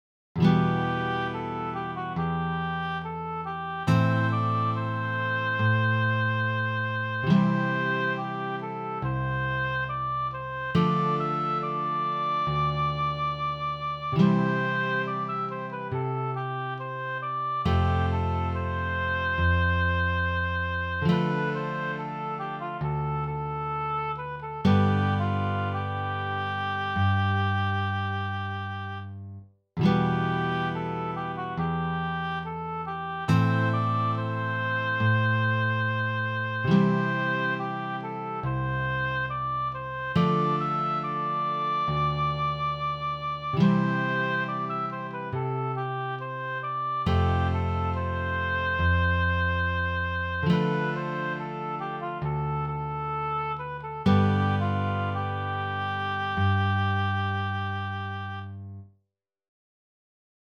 Gli inni, in quanto canzoni, sono spesso cantati in modo ritmico, il che è una soluzione certamente preferibile all'equalismo gregoriano.
midi